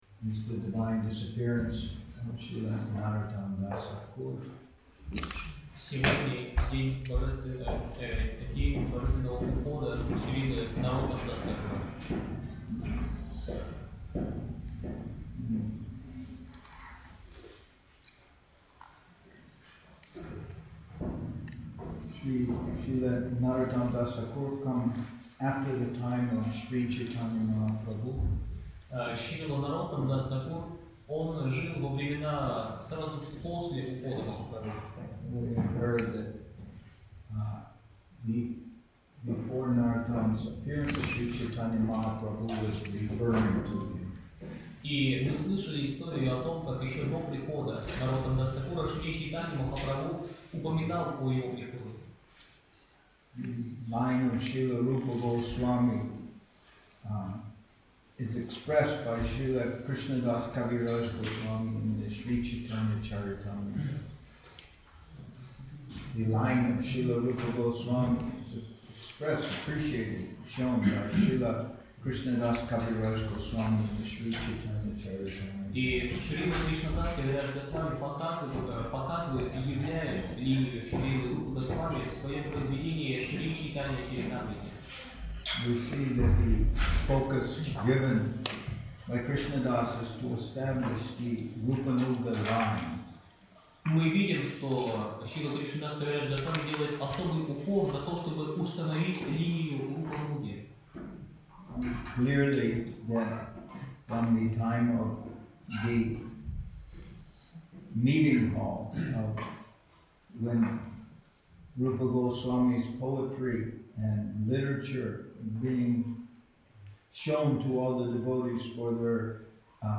Песня "Шри Рупа Манджари пада".